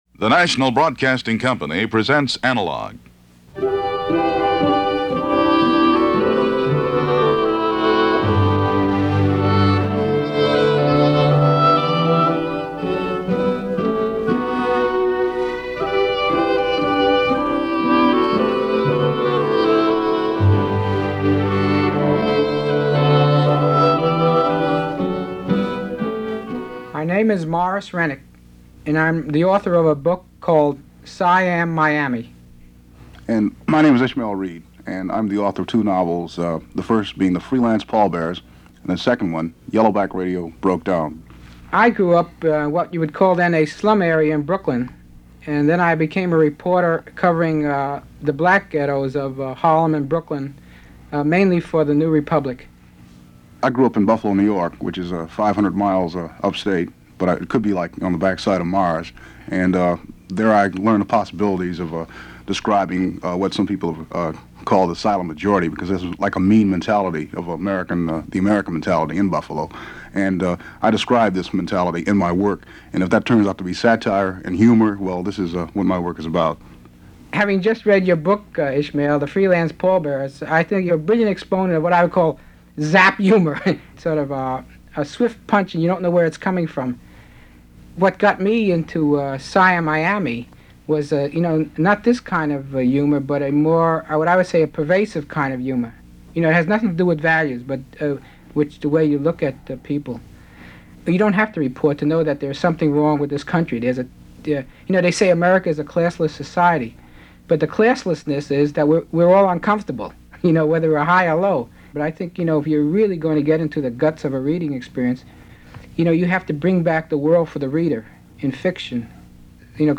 What’s great about this program is that, even though there are many differences of opinion from both writers, it never becomes reduced to name calling or shouting. It’s intelligent discourse in the best possible light.